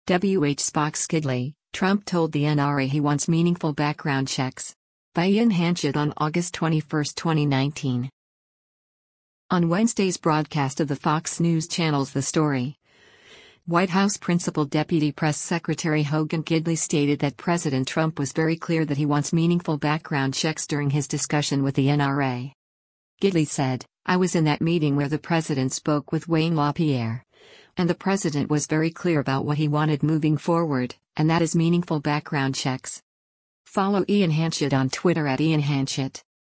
On Wednesday’s broadcast of the Fox News Channel’s “The Story,” White House Principal Deputy Press Secretary Hogan Gidley stated that President Trump “was very clear” that he wants “meaningful background checks” during his discussion with the NRA.